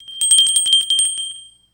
bell2
bell brass ding jingle ring ringing ting sound effect free sound royalty free Sound Effects